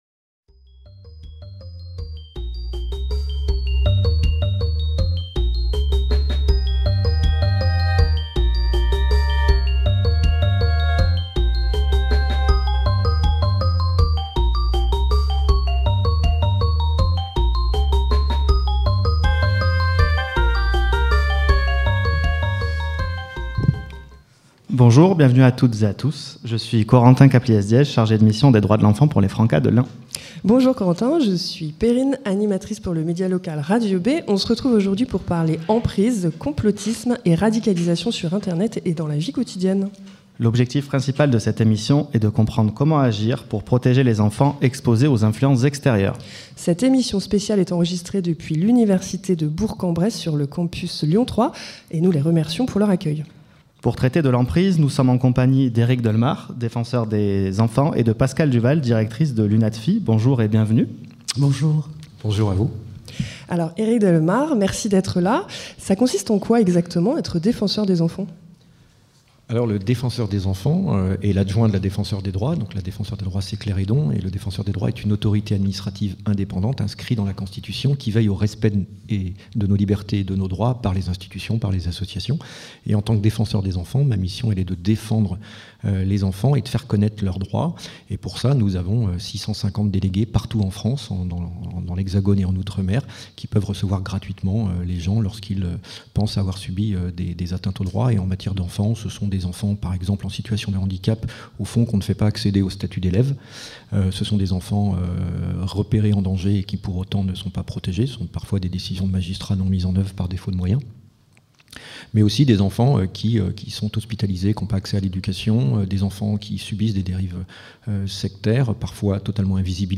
Enregistrée à l'Université de Bourg-en-Bresse, cette émission spéciale propose une heure d'échanges pour mieux comprendre les mécanismes de l'emprise, leurs liens avec le complotisme et la radicalisation, et leurs effets spécifiques sur les enfants et les adolescents.